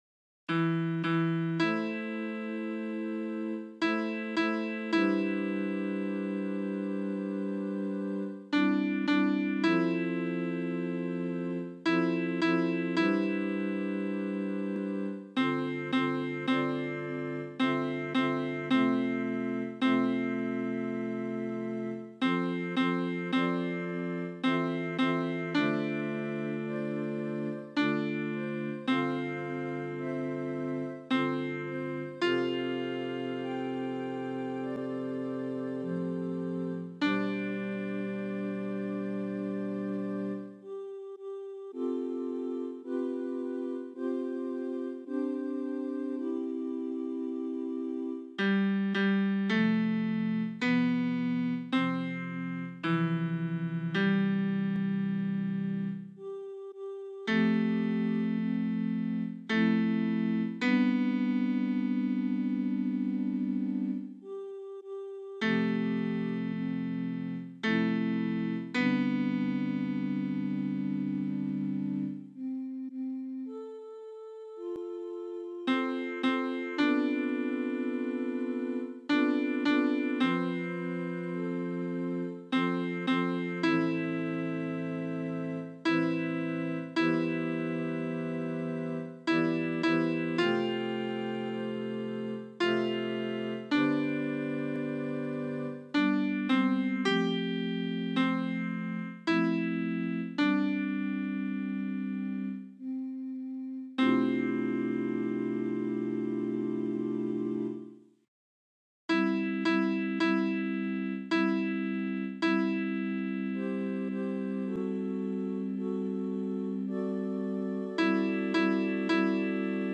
Versions piano
TENOR 1